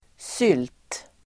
Ladda ner uttalet
Uttal: [syl:t]